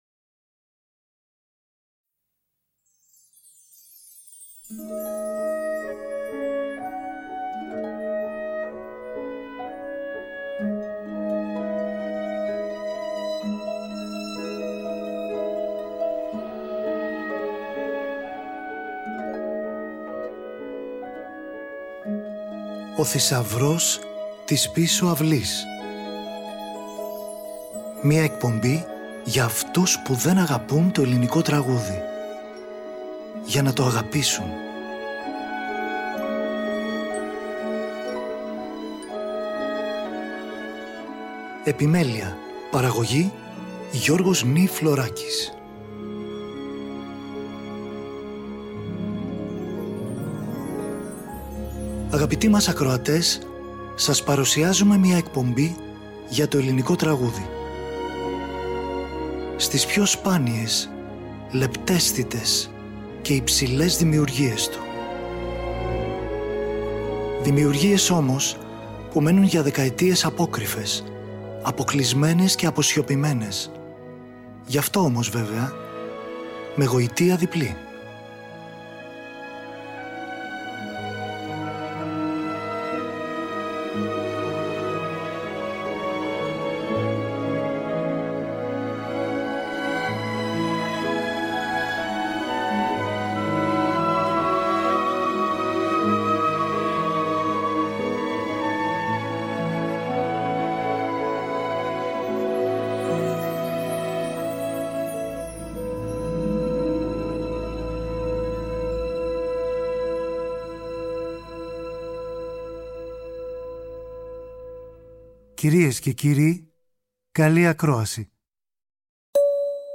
ακούμε τον κύκλο τραγουδιών